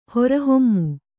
♪ hora hommu